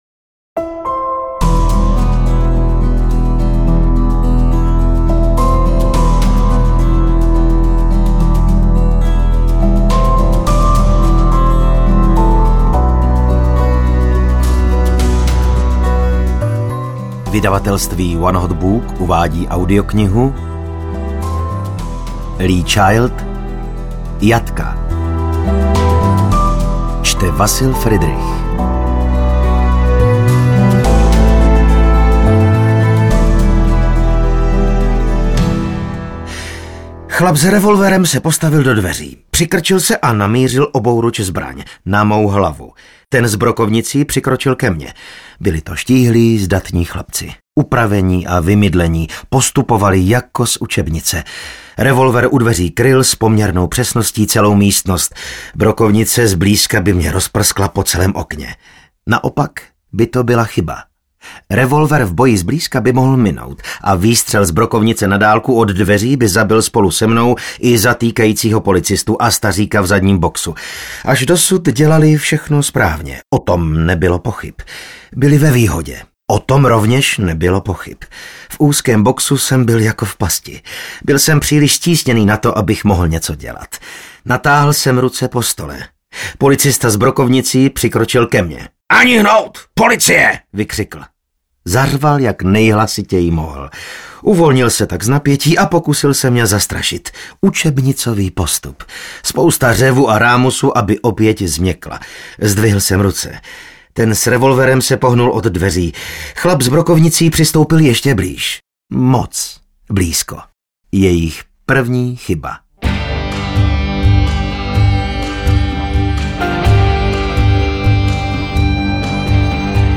3x Reacher #1 audiokniha
Ukázka z knihy
• InterpretVasil Fridrich